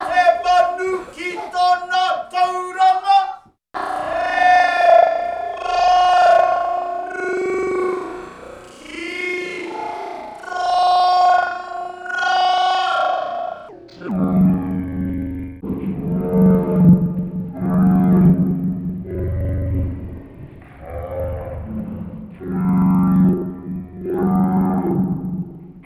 First the sample plays in one shot mode, then stretch mode to 8 bars at 94 BPM, then pitched two octaves down
dang, love that! the two octaves down sounded rich.:ok_hand:t3: